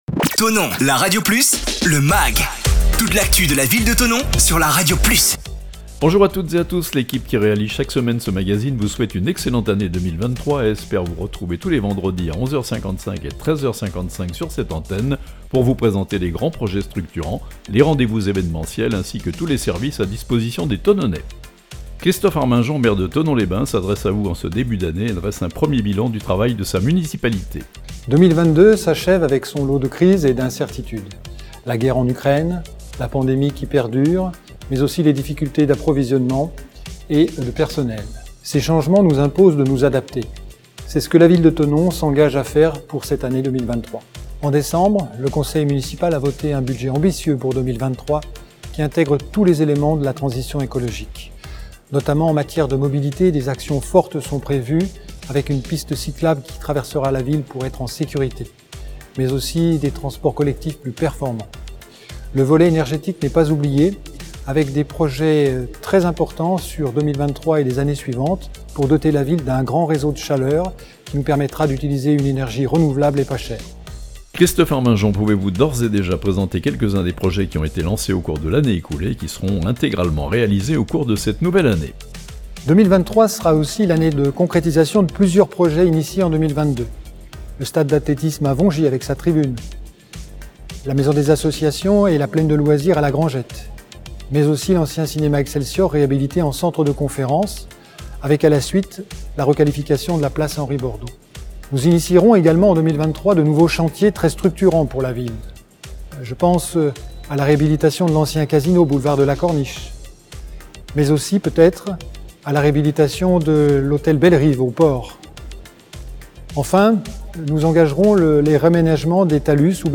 Le Maire de Thonon, Christophe Arminjon, revient sur l'année écoulée et présente ses voeux à la population de la ville.